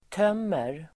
Uttal: [t'öm:er]